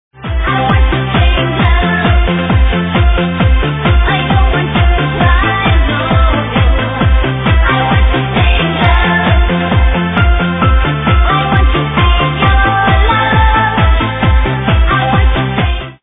Talking Someone Please help with this Vocal Tune
ok heres the song with the chorus
i dunno but that track is HORRIBLE sounds like that barbie girl track haha